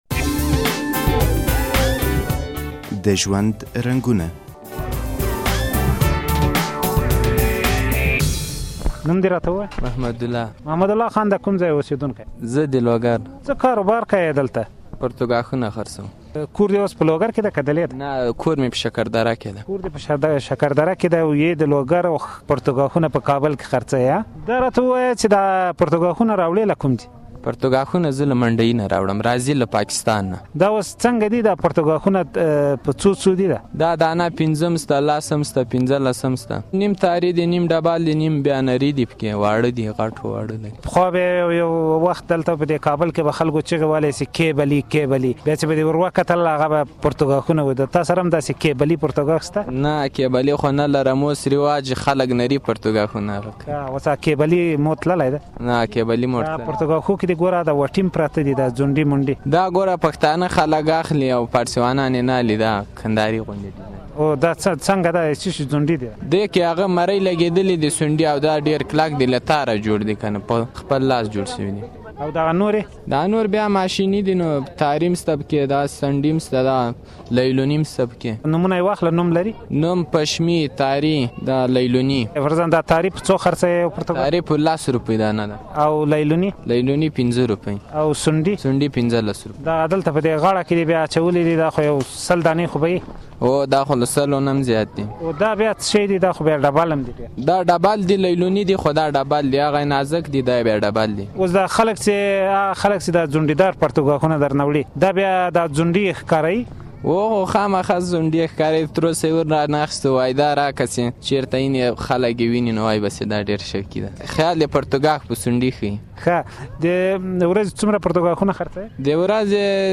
پرتوګاښ پلورونکي سره مرکه